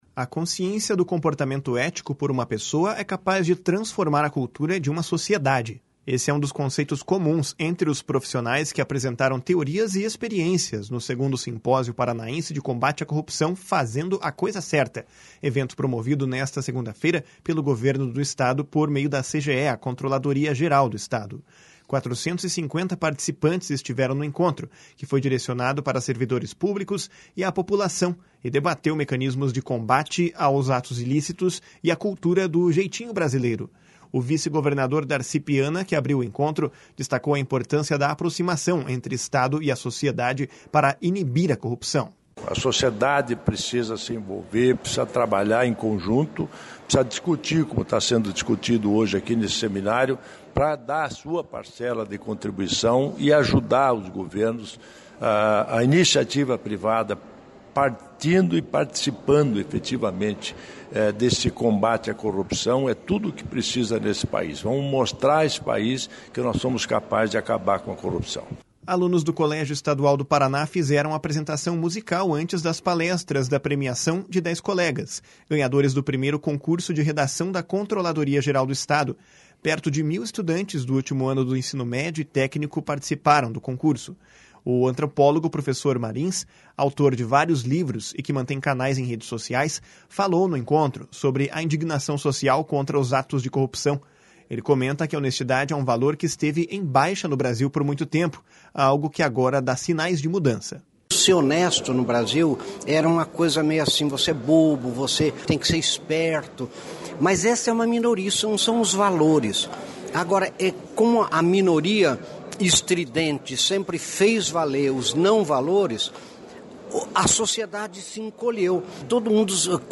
Esse é um dos conceitos comuns entre os profissionais que apresentaram teorias e experiências no segundo Simpósio Paranaense de Combate à Corrupção – “Fazendo a coisa certa”, evento promovido nesta segunda-feira pelo Governo do Paraná, por meio da CGE, a Controladoria-Geral do Estado. 450 participantes estiveram no encontro, que foi direcionado para servidores públicos e à população e debateu mecanismos de combate aos atos ilícitos e a cultura do “jeitinho brasileiro”. O vice-governador Darci Piana, que abriu o encontro, destacou a importância da aproximação entre Estado e a sociedade para inibir a corrupção. // SONORA DARCI PIANA //